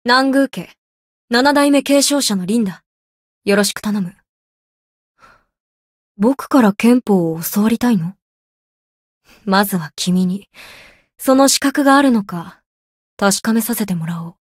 灵魂潮汐-南宫凛-人偶初识语音.ogg